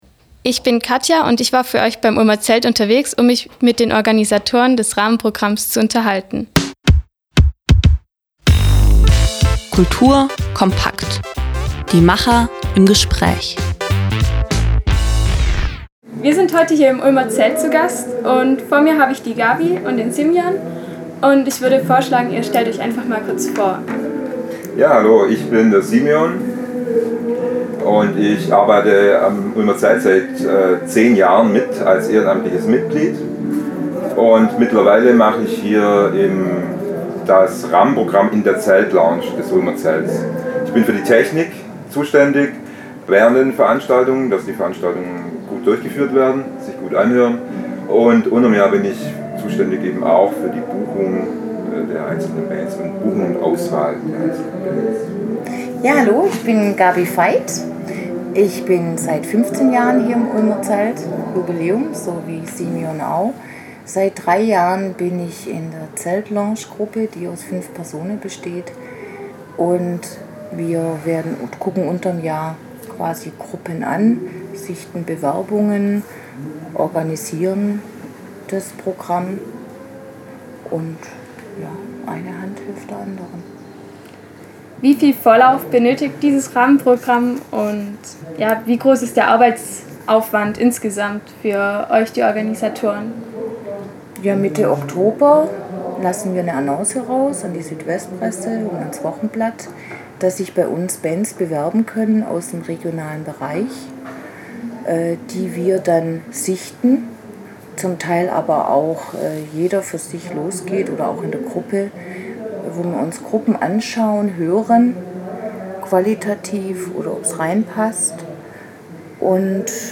Ein Interview mit den Organisatoren des Rahmenprogramms im Ulmer Zelt.
rahmenprogramm_-_ulmer_zelt_-_interview.mp3